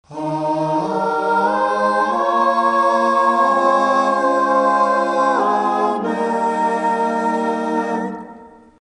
female vocals
Choral    Pop    Home